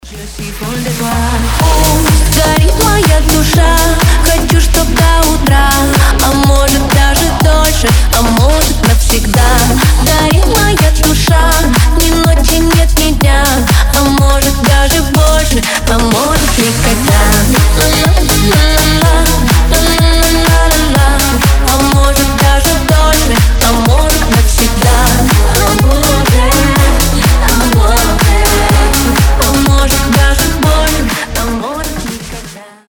громкие
зажигательные
женский голос
Club House
ремиксы